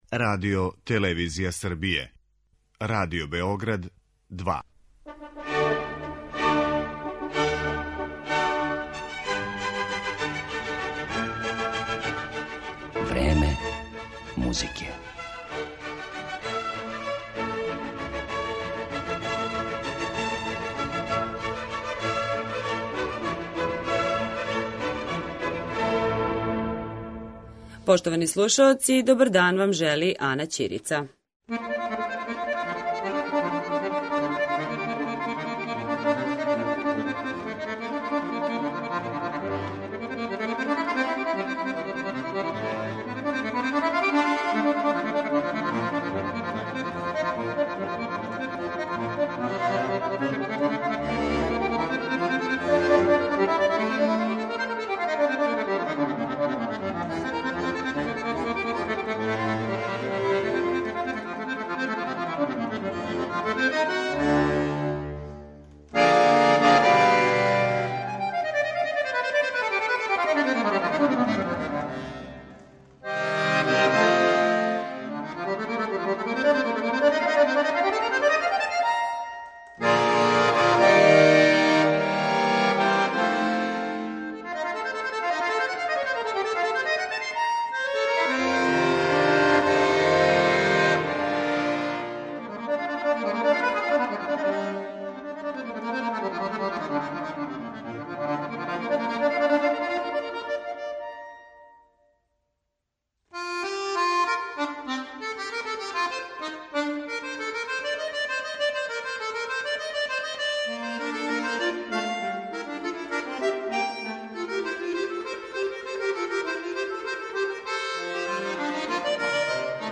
уметник на хармоници